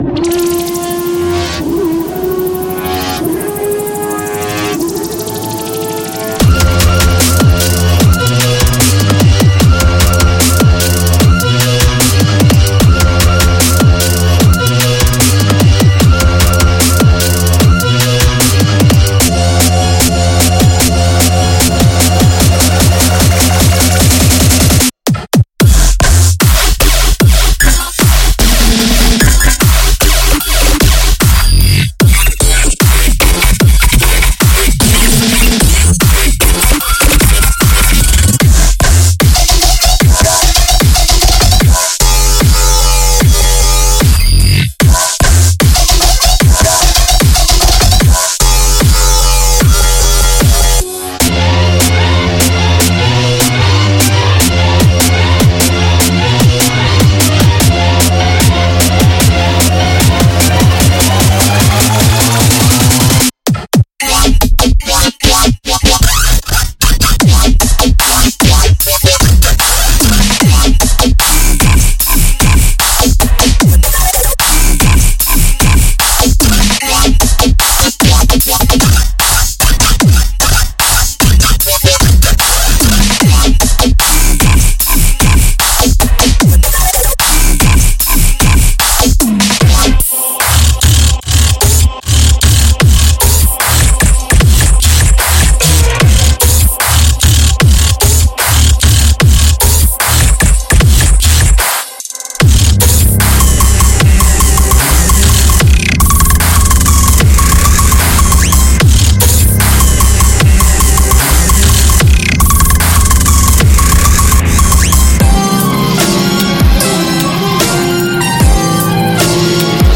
乐，重击小军鼓，振奋人心的旋律，为您的下一曲锦上添花。
如果您追求最肮脏，最讨厌的声音，那么这款背包适合您。